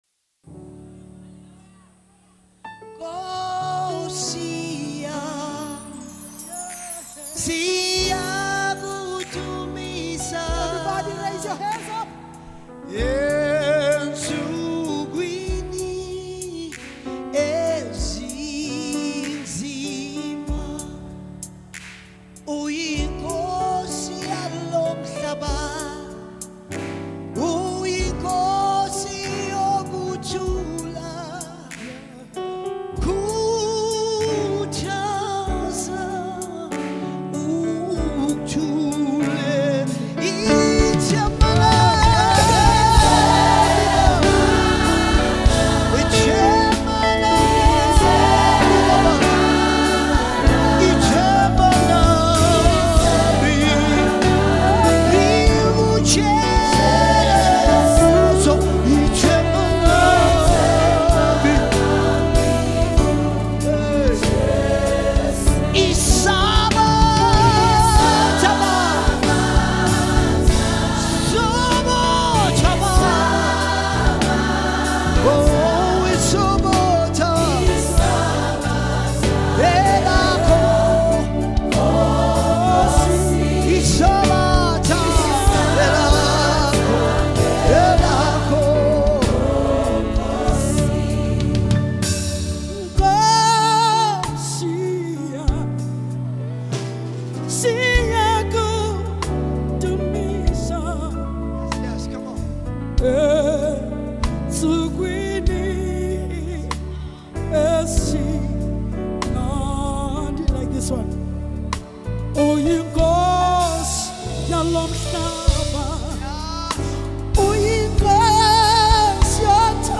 Latest 2024 Live South African Worship Song